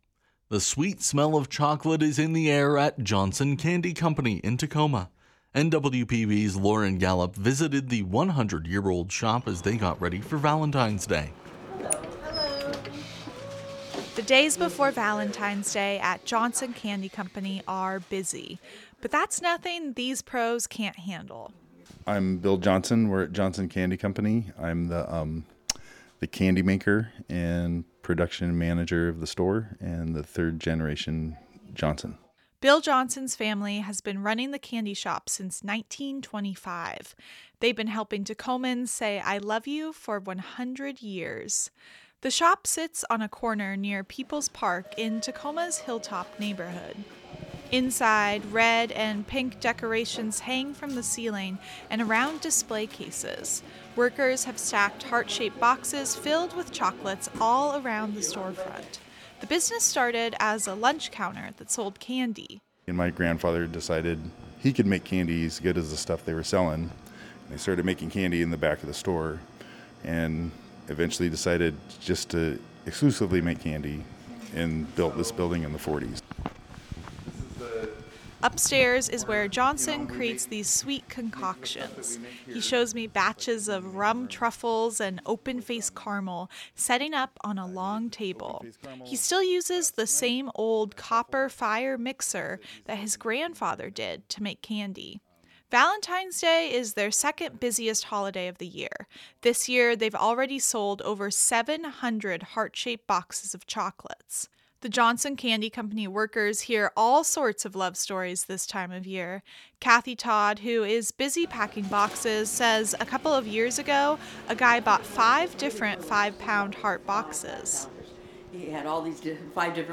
Every few minutes, a bell rings as another customer enters in search of chocolate-covered cherries or rum truffles.